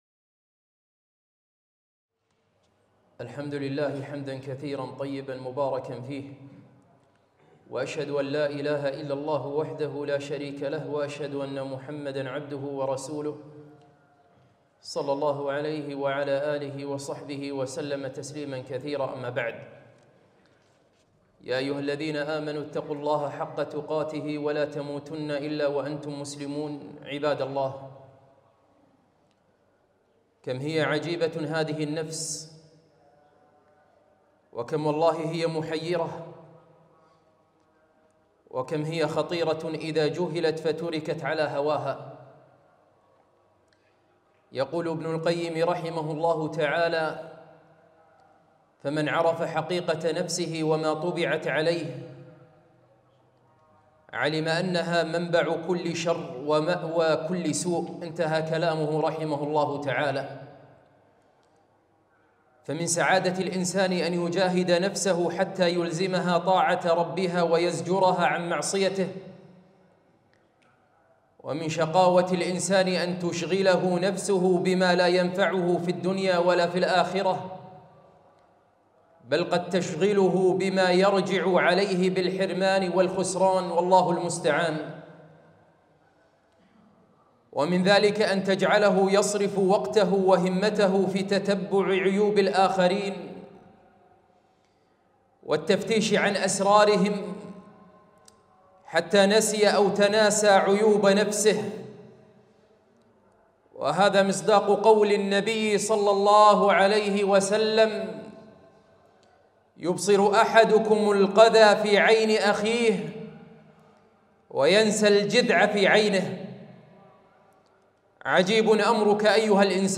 خطبة - الانشغال بعيوب النفس عن عيوب الآخرين